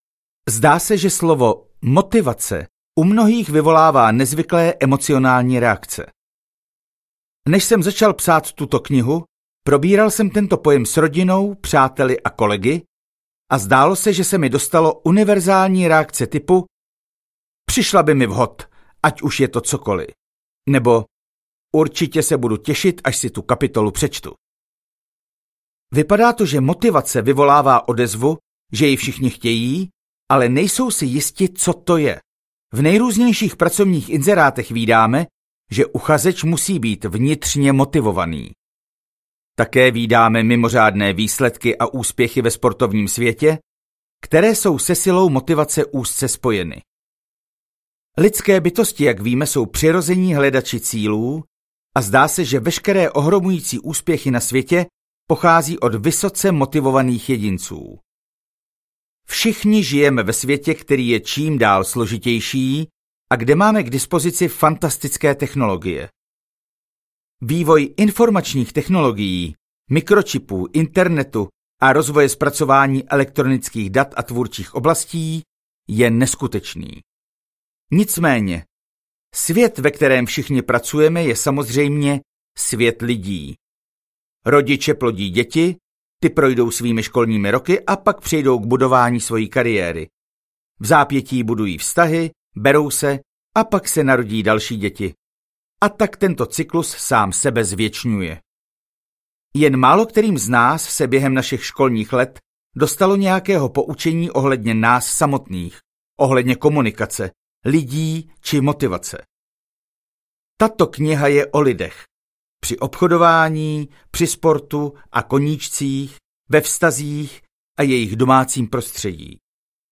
Motivací k Vítězství audiokniha
Ukázka z knihy